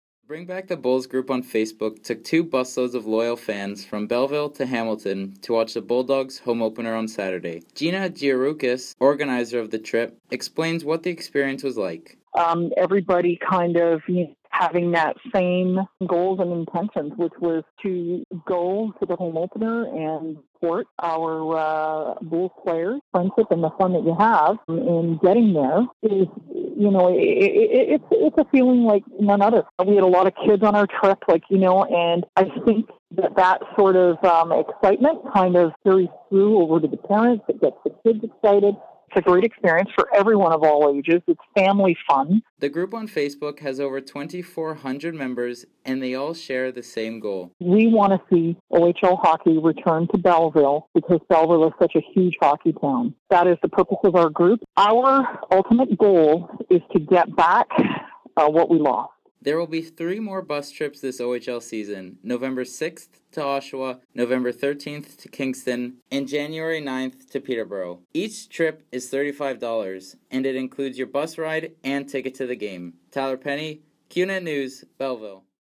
Qnet News spoke to fans who went about their experience.